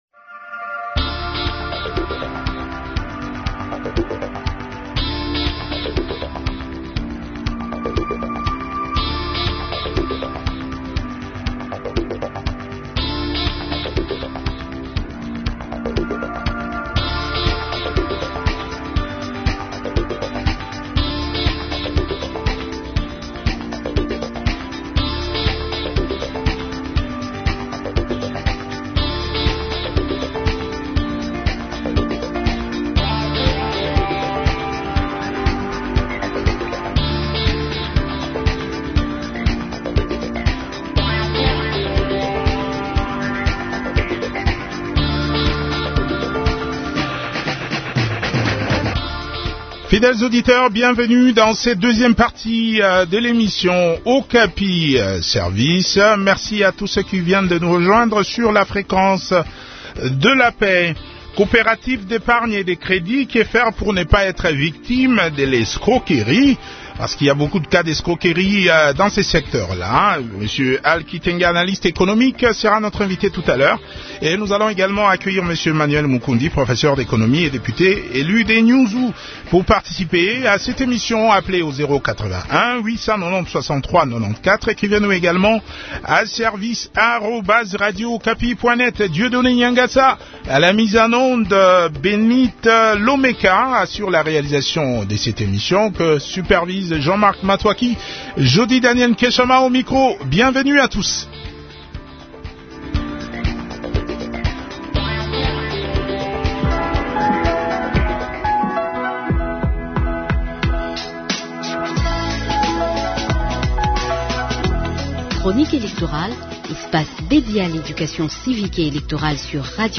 analyste économique
professeur d'économie.